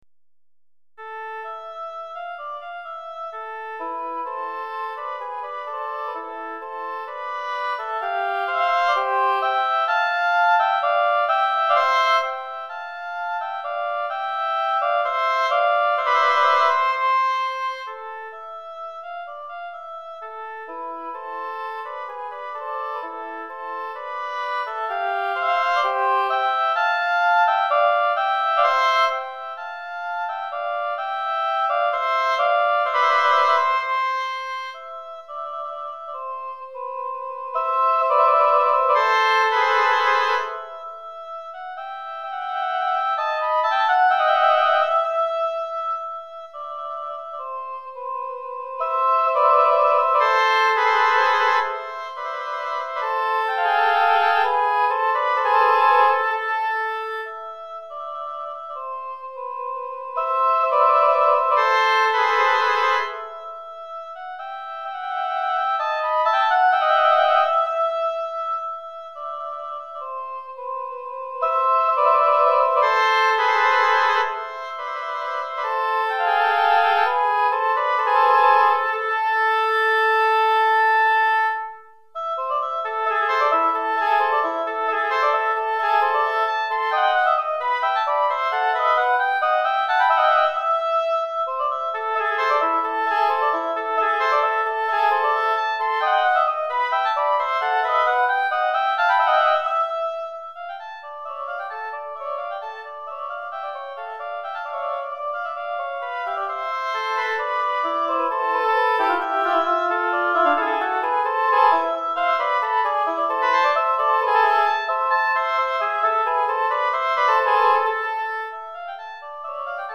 2 Hautbois